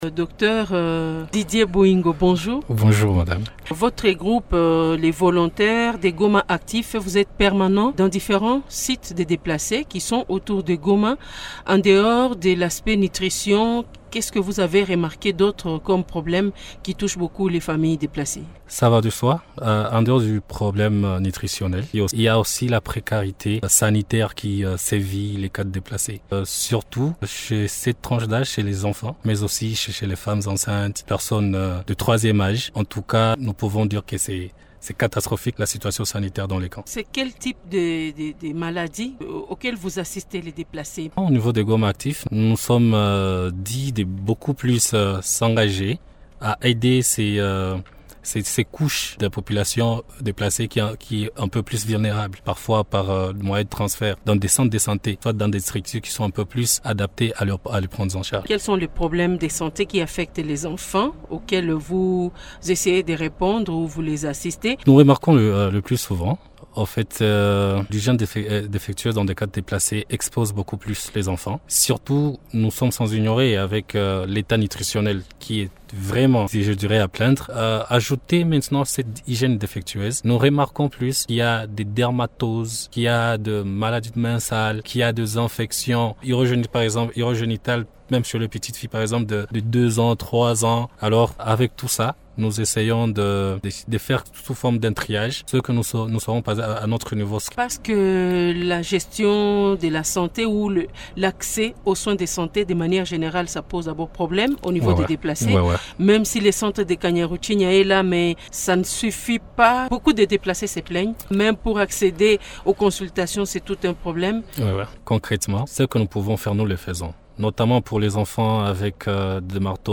Invité de Radio Okapi ce lundi 22 juillet
au cours de cet entretien avec Radio Okapi